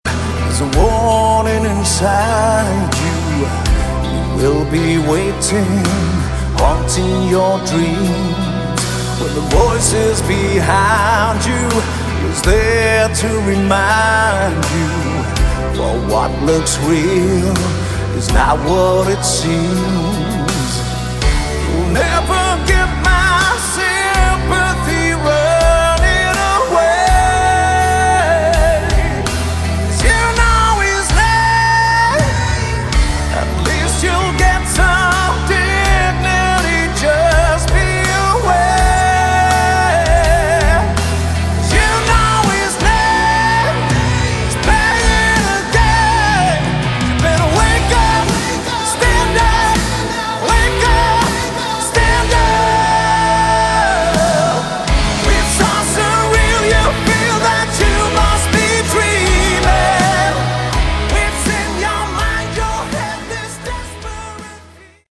Category: Modern Hard Rock
lead vocals, backing vocals